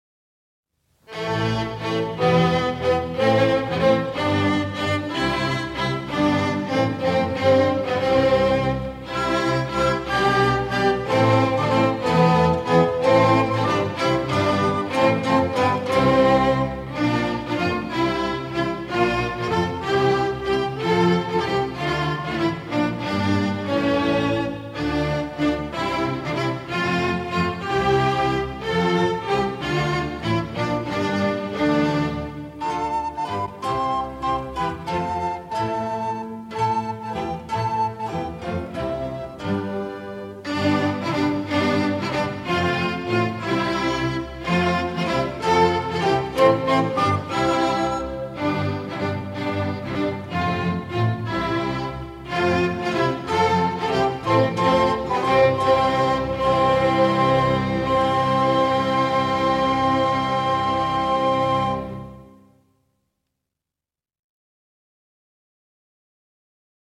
Un score tout en nuance et en retenue